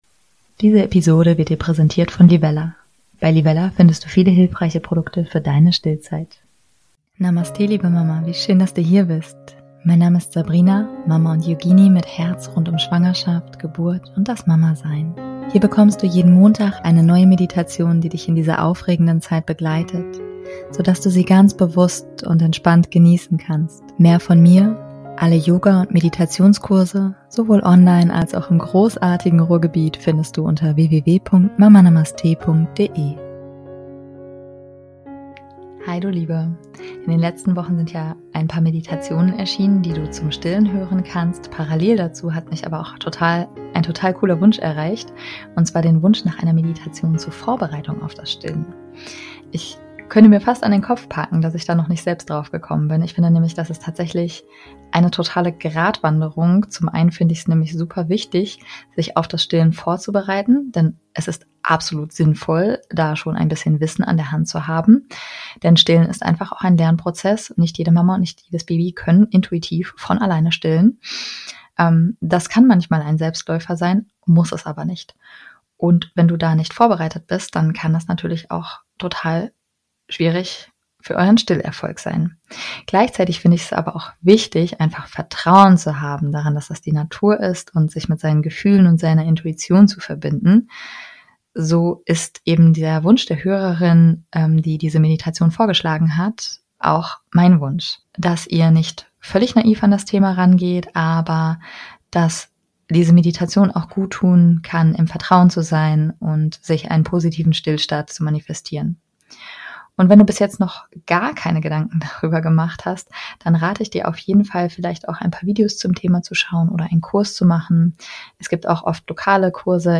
#176 - Meditation zur Vorbereitung auf das Stillen